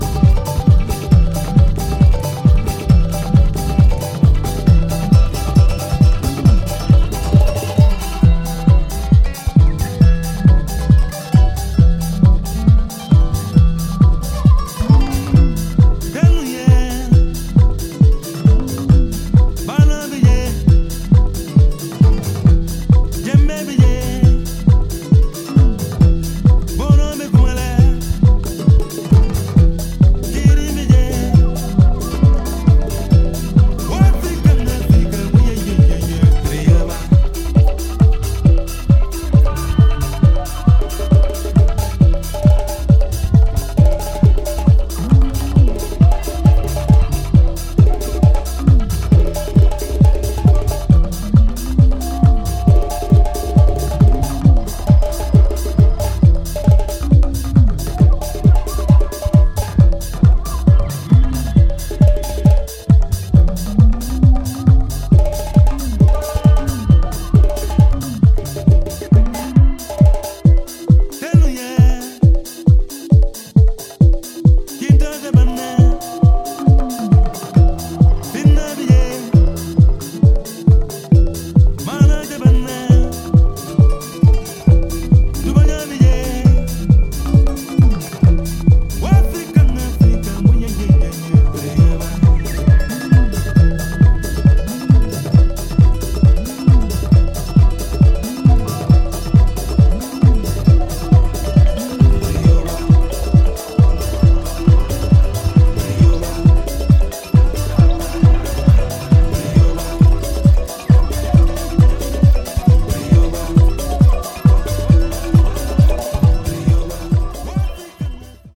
edits and overdubs of African vibrational sounds and rhythms